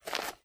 High Quality Footsteps
STEPS Dirt, Walk 11.wav